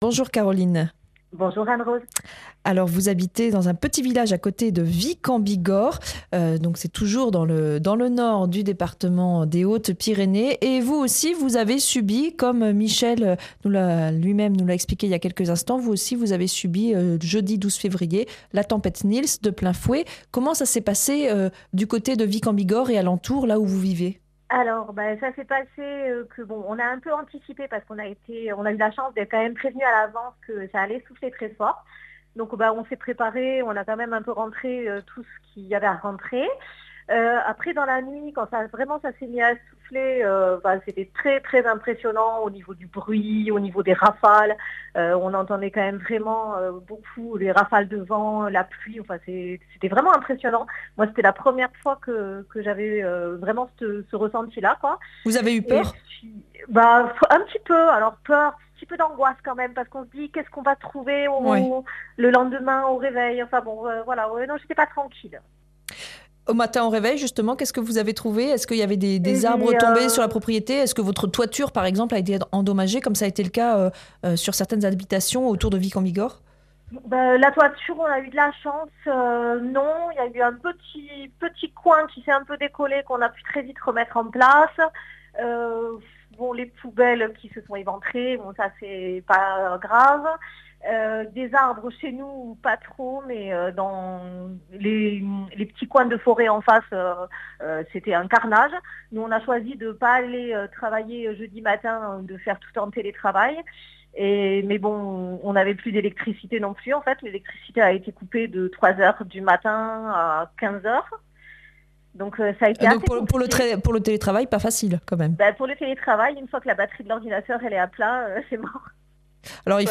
mardi 17 février 2026 Interview et reportage Durée 10 min
Une émission présentée par